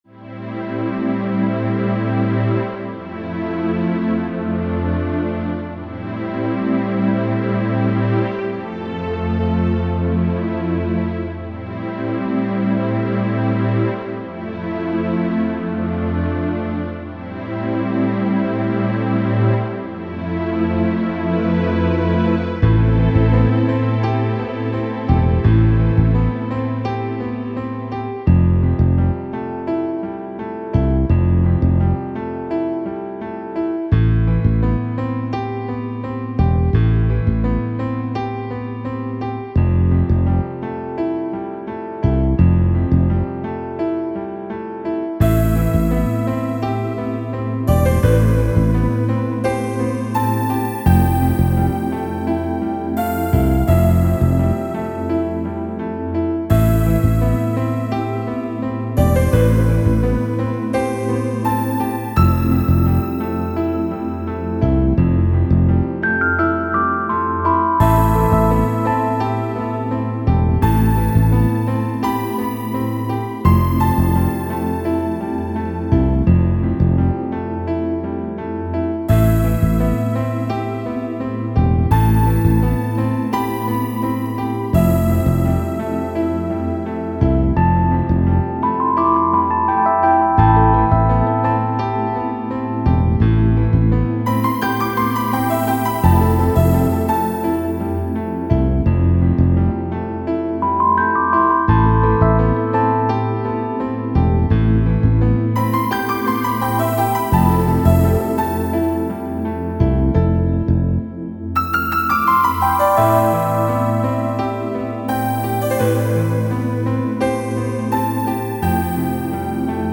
じめじめした洞窟をイメージした RPG のダンジョン曲です。神秘的な感じです。